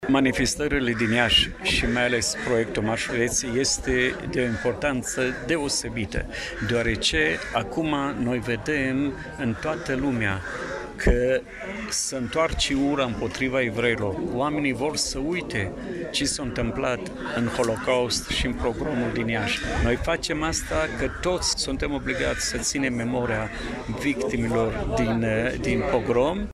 La Cimitirul Evreiesc, a avut loc o manifestare în cadrul căreia au fost rememorate evenimentele triste petrecute în urmă cu 83 de ani, dar și un moment simbolic de recunoaştere a meritelor deosebite ale comunităţii evreieşti în dezvoltarea Iaşiului.